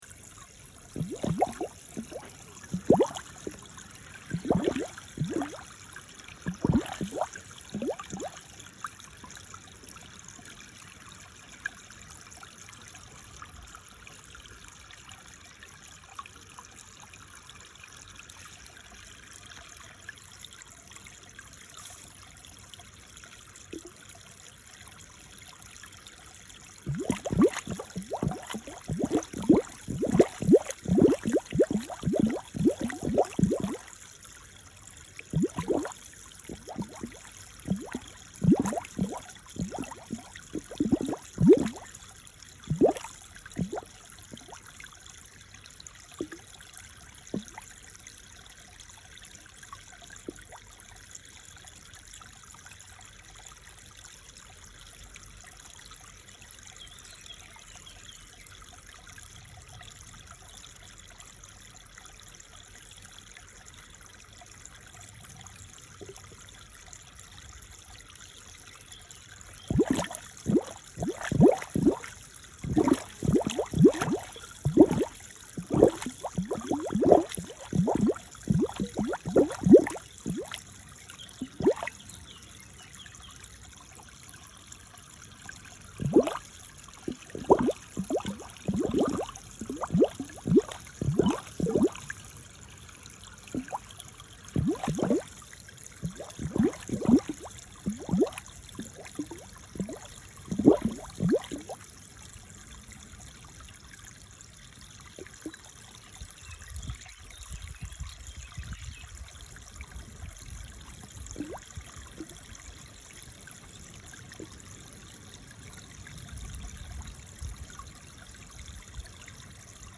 Звуки родника
На этой странице вы можете слушать онлайн или бесплатно скачать успокаивающие записи журчания свежей ключевой воды.